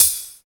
134 HAT 2.wav